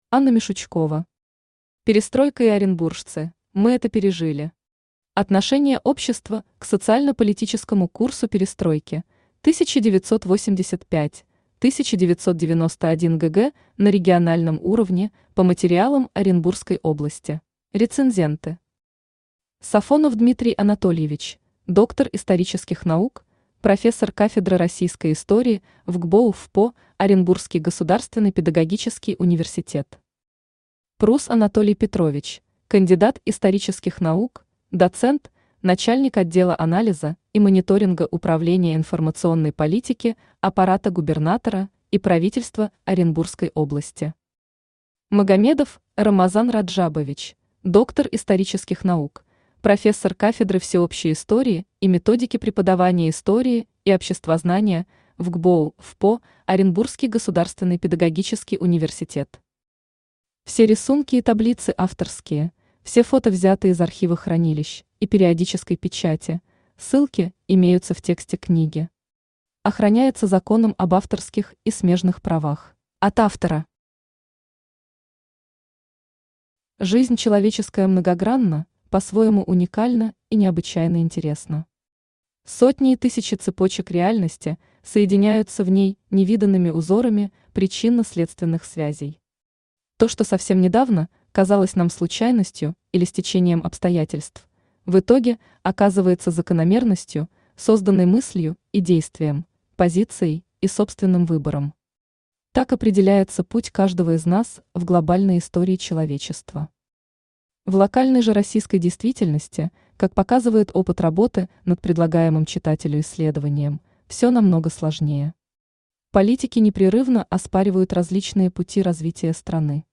Аудиокнига Перестройка и оренбуржцы: мы это пережили!
Читает аудиокнигу Авточтец ЛитРес.